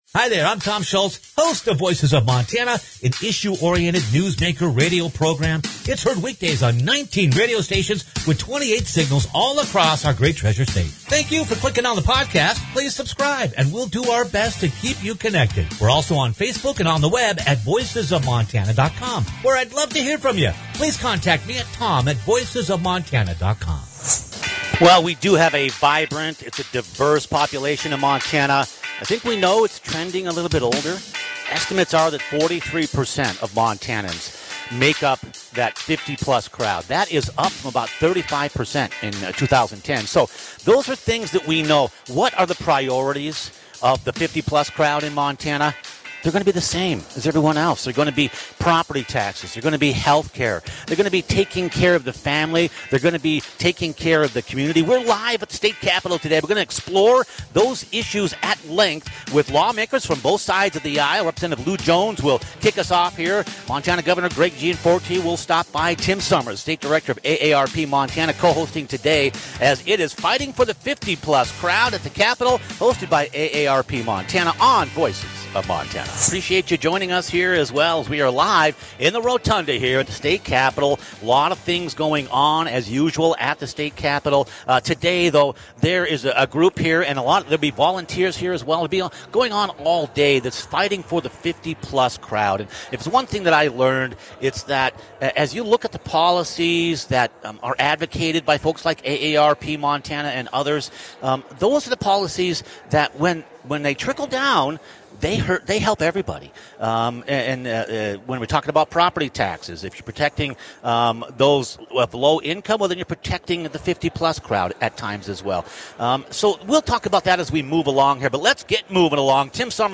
LIVE from the Capitol – Fighting for the 50+ - Voices of Montana